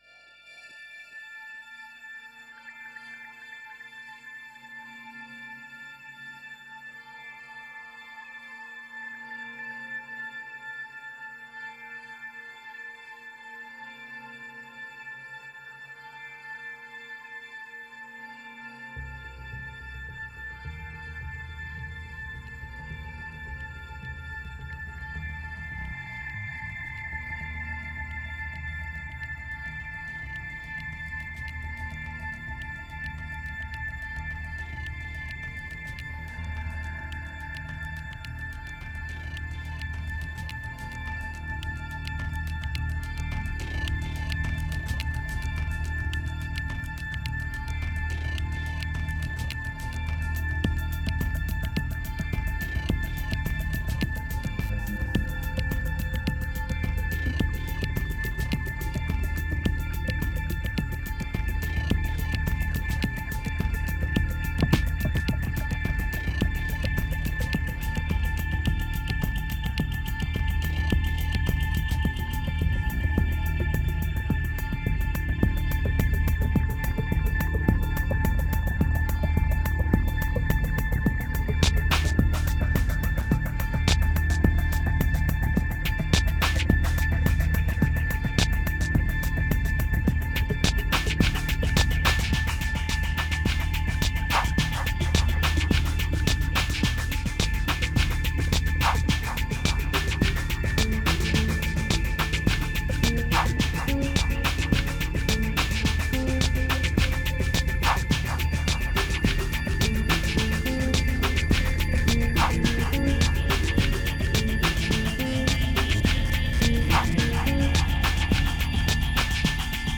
2451📈 - 20%🤔 - 107BPM🔊 - 2011-01-26📅 - -89🌟